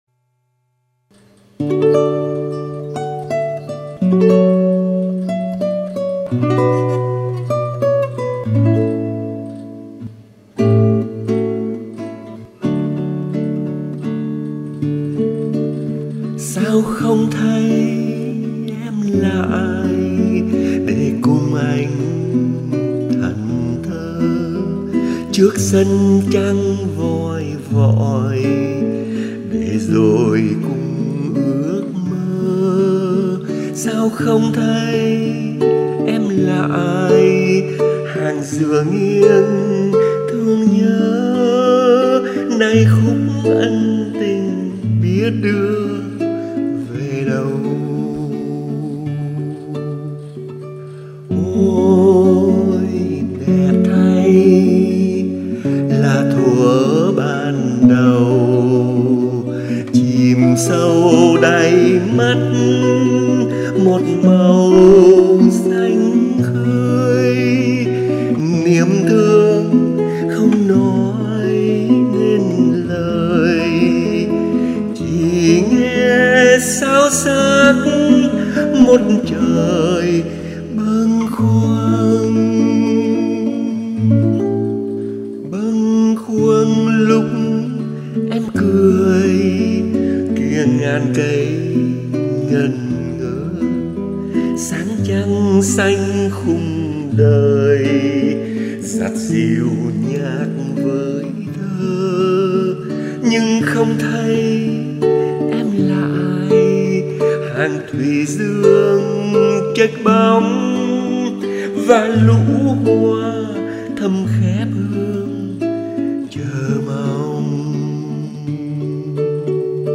là một ca khúc trữ t́nh nhẹ nhàng
Hát theo nhịp 3/4 nguyên thuỷ dễ lột trần ư của bài hát hơn.